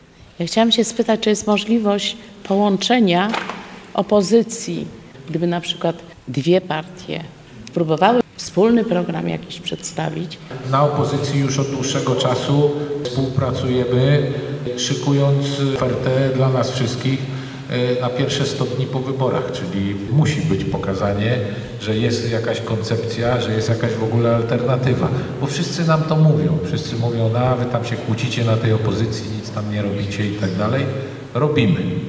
W Domu Kultury Kolejarza wiceprzewodniczący Nowej Lewicy Dariusz Wieczorek spotkał się z mieszkańcami Stargardu. Były pytania o gospodarkę, drożyznę czy połączenie i współpracę partii opozycyjnych w nadchodzących wyborach.